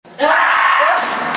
Sound Effects
Loud Scream